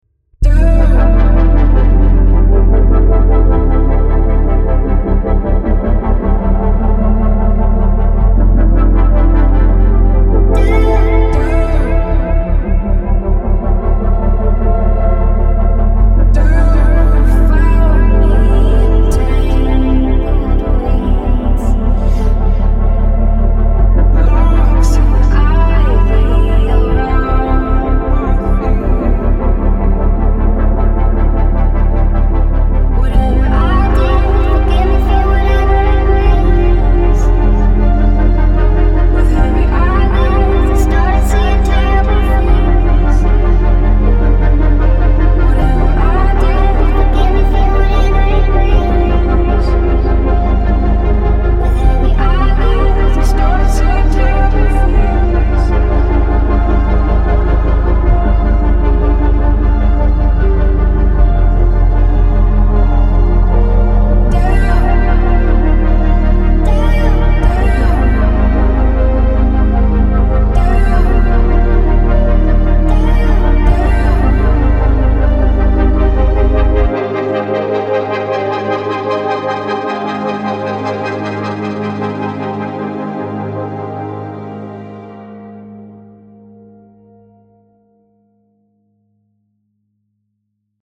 复古合成综合音源 Teletone Audio Ondine KONTAKT-音频fun
Teletone Audio Ondine 是一款基于 Kontakt 的虚拟乐器，它使用了一些世界上最具标志性的复古合成器的采样，创造出了一些轻盈而迷幻的声音。这款乐器被描述为 Scarbo 的前传，它探索了合成器声音的明亮和催眠的一面。
Ondine 提供了50多种乐器和25种多重音色，涵盖了键盘、垫音、低音、主音和合成器等各种声音。